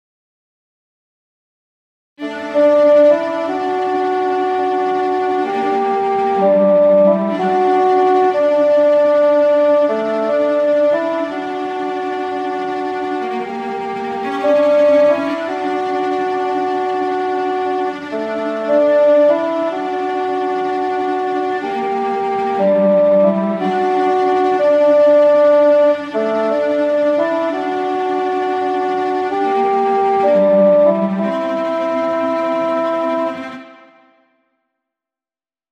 remixed orchestral composition